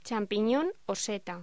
Locución: Champiñón o seta
voz